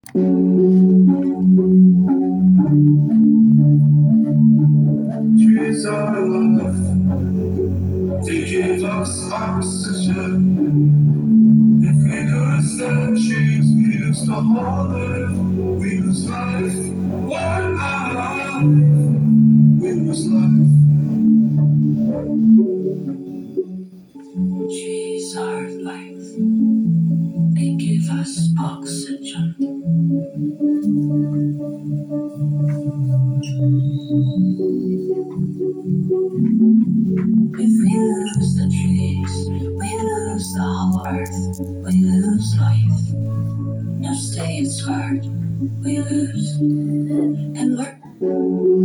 Στα πλαίσια του προγράμματος "The giving tree" τα παιδιά έγραψαν ποίημα για τα δέντρα και μελοποιήθηκε με τη βοήθεια του προγράμματος Suno AI.